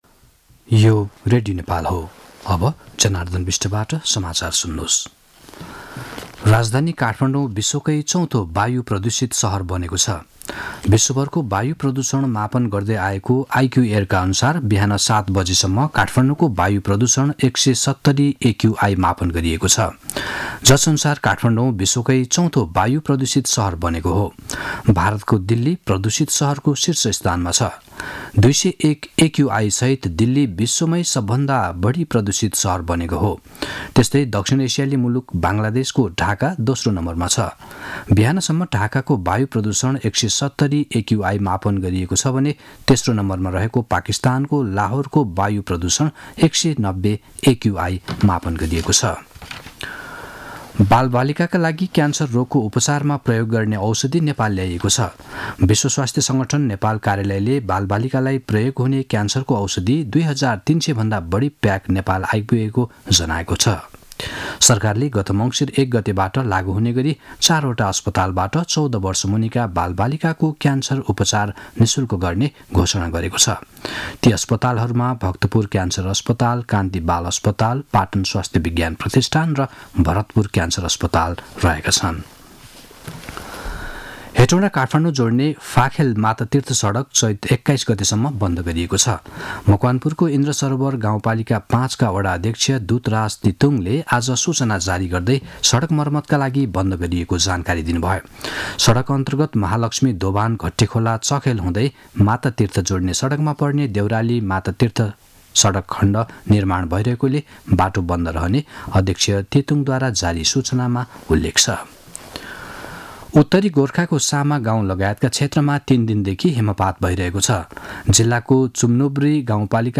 मध्यान्ह १२ बजेको नेपाली समाचार : ६ फागुन , २०८१